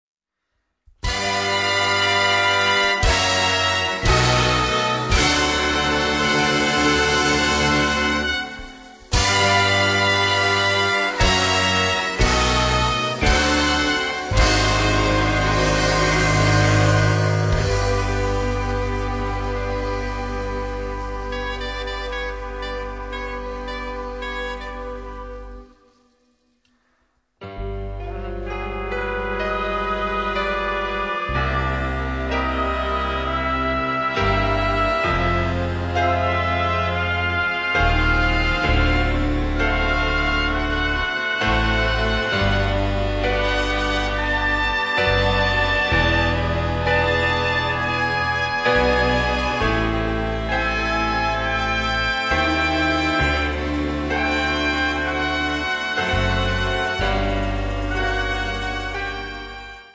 Composer, conductor, band leader, jazz pianist